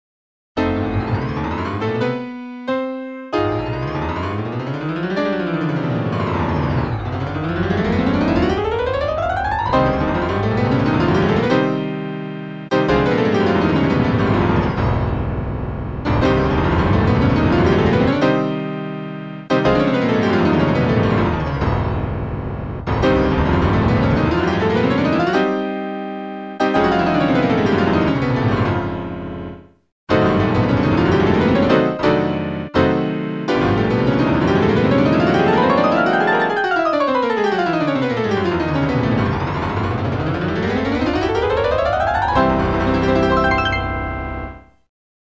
MUSICALITY TEST
This portion of the test ends with the largest chord in the test roll. Sixteen notes are played simultaneously. Shortly after the notes are played, the automatic sustaining pedal is activated, and it stays on until just after the perforations have ended.